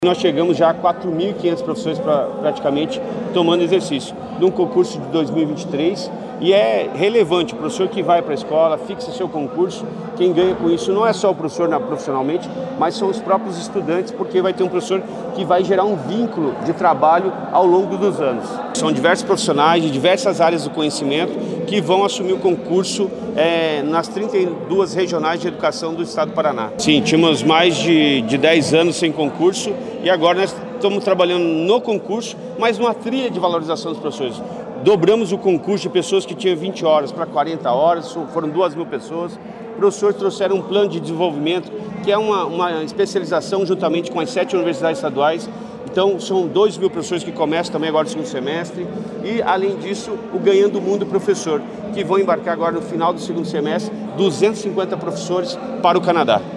Sonora do secretário da Educação, Roni Miranda, sobre a nomeação de mais 988 professores da rede estadual